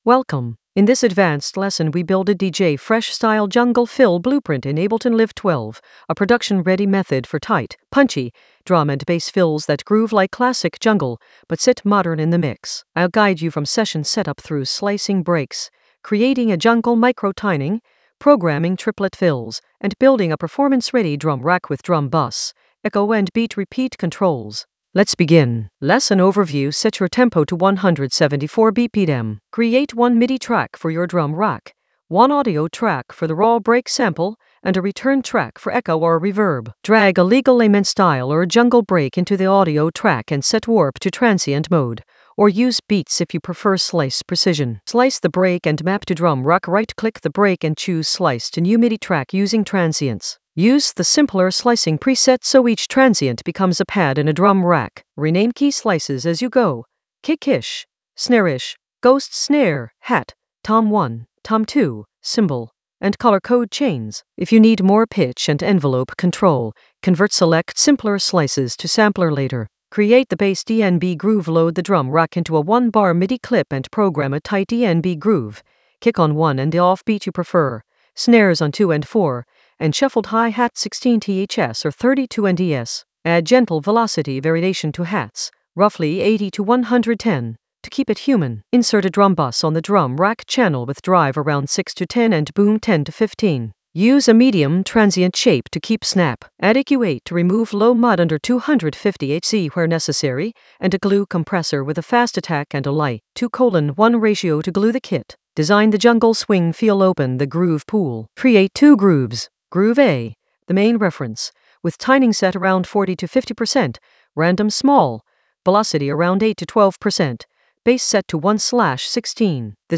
An AI-generated advanced Ableton lesson focused on DJ Fresh Ableton Live 12 jungle fill blueprint with jungle swing in the Groove area of drum and bass production.
Narrated lesson audio
The voice track includes the tutorial plus extra teacher commentary.